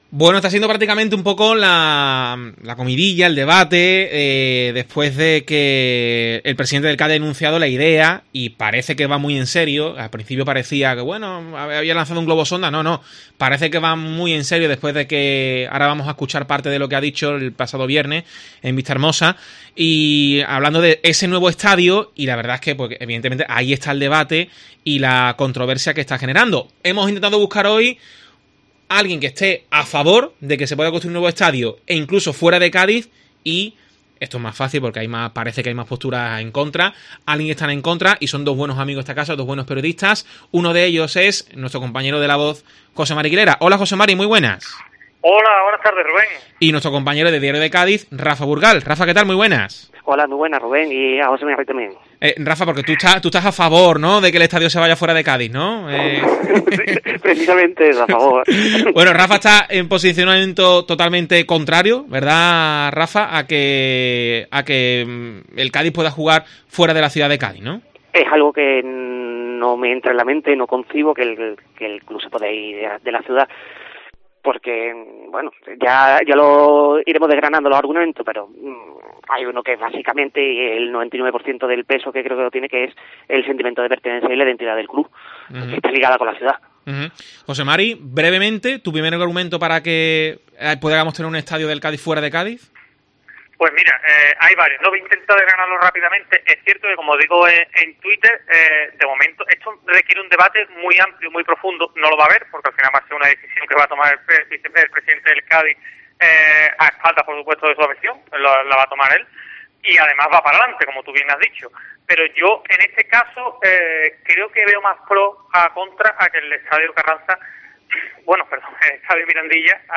El debate sobre un nuevo estadio para el Cádiz CF
Otros, piensan que un estadio nuevo aunque fuera en otra localidad sería una gran oportunidad para el club. En Deportes COPE, dos periodistas opinan.